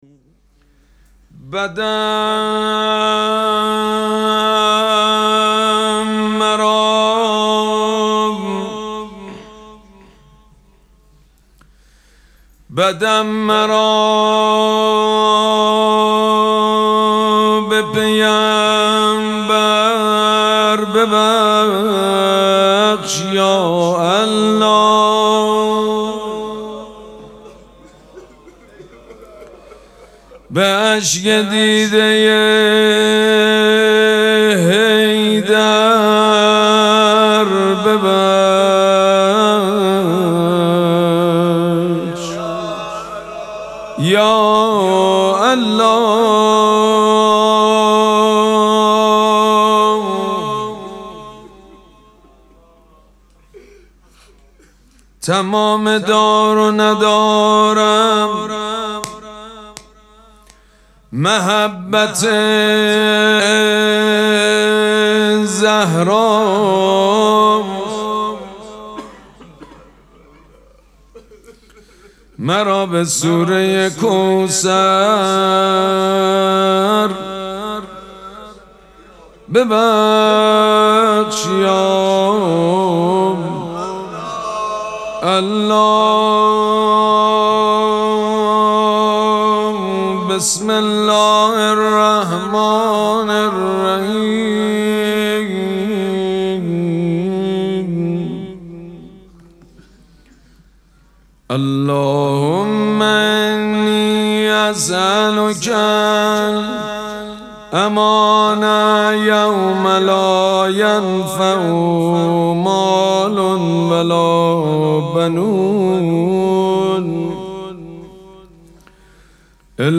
مراسم مناجات شب چهاردهم ماه مبارک رمضان
حسینیه ریحانه الحسین سلام الله علیها
مناجات
حاج سید مجید بنی فاطمه